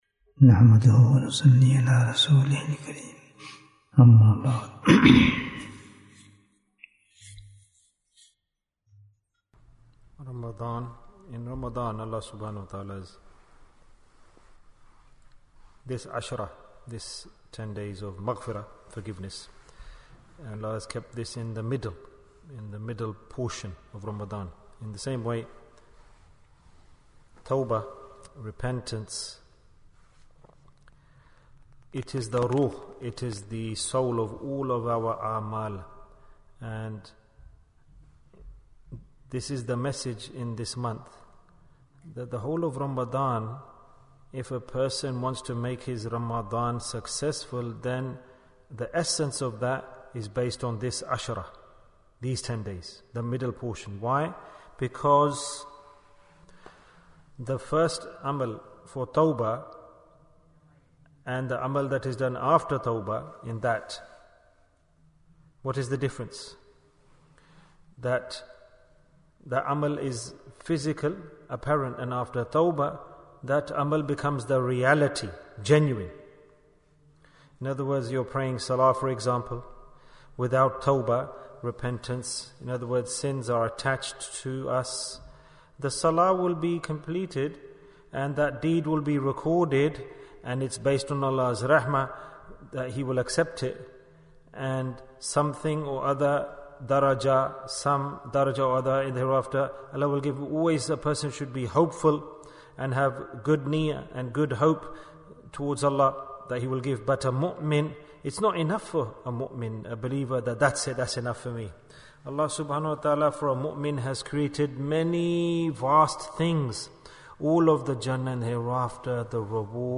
What is the Difference of Deeds Before & After Tawbah? Bayan, 30 minutes7th April, 2023